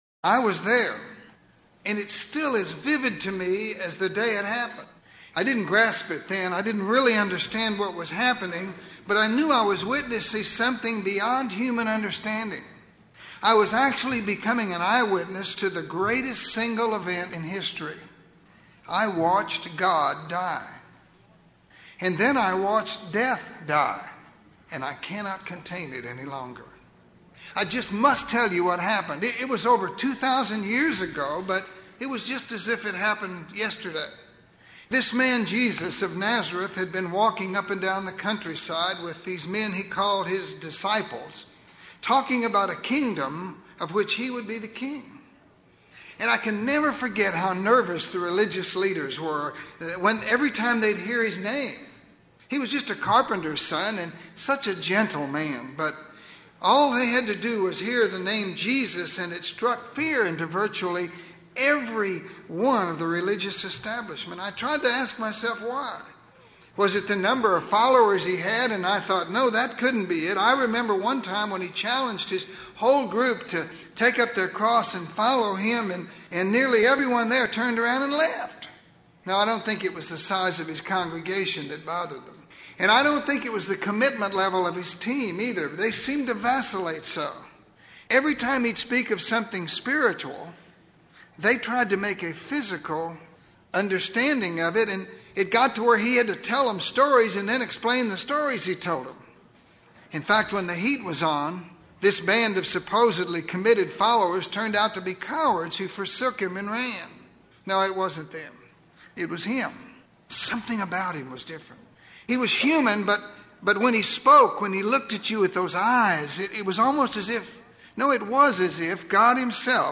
This lesson provides an account of the crucifixion and resurrection of Christ.